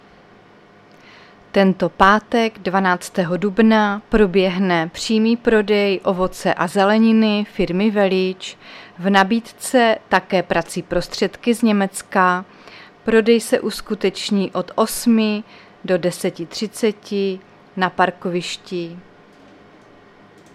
Záznam hlášení místního rozhlasu 11.4.2024
Zařazení: Rozhlas